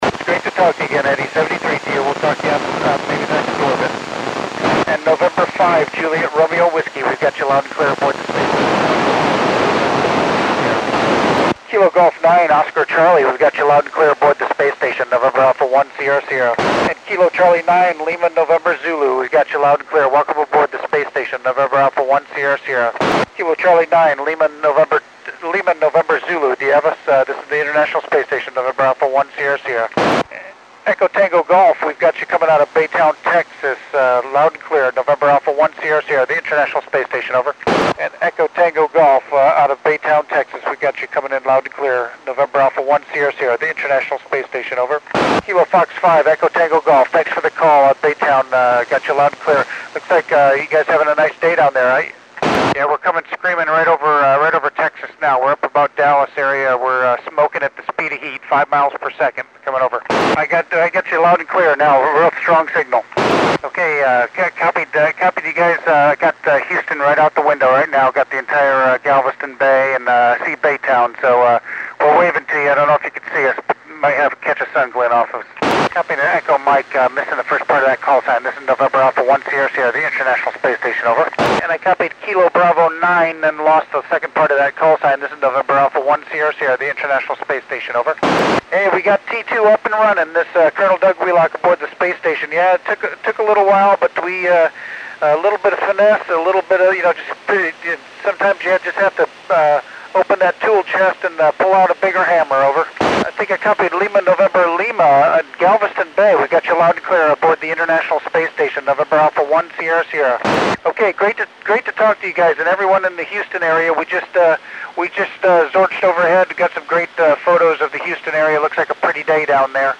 Col. Doug Wheelock (NA1SS) works U.S., Mexican, and Cuban stations on 03 October 2010 at 1749 UTC.
10 Degree pass for me, so a bit scratchy.